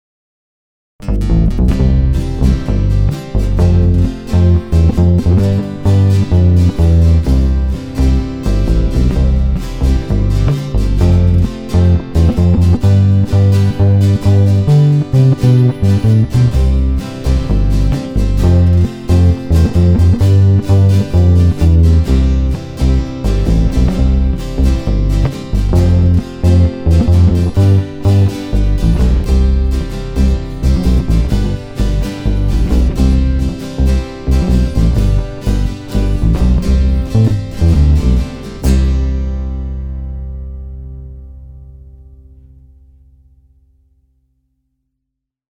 Ovšem pro dokreslení, jak nástroj vyzní v kontextu kapely, jsem nahrávku doplnil dvojicí akustických kytar a použil i postprocesing jako kompresi a drobnou ekvalizaci.
Ukázka "s kapelou"
Myslím, že by nikdo neřekl, že se do tak malého nástroje vejde tak velký zvuk.